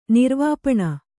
♪ nirvāpaṇa